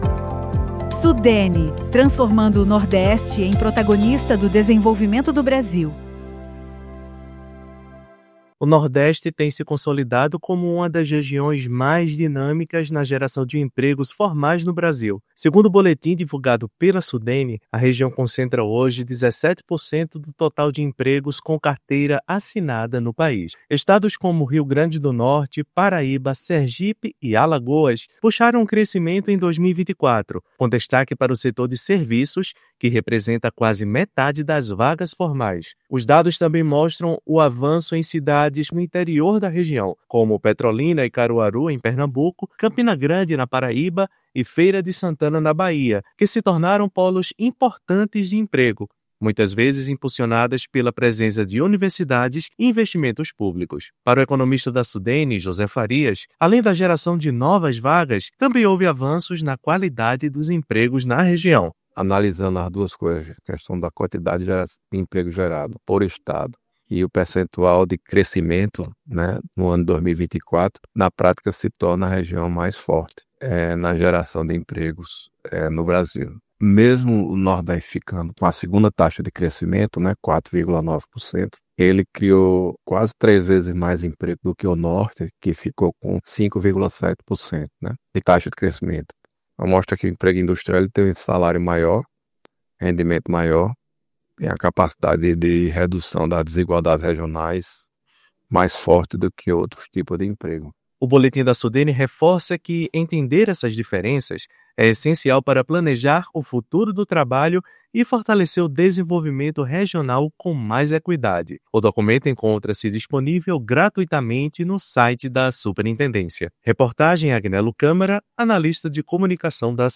AUDIORELEASE: Nordeste concentra 17% do estoque de empregos formais do País, segundo a Sudene